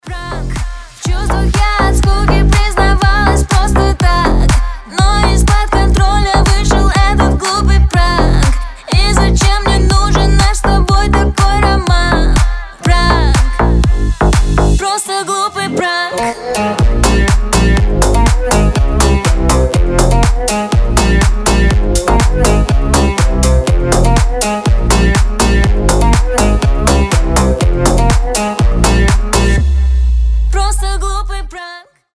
• Качество: 320, Stereo
гитара
басы
восточные
slap house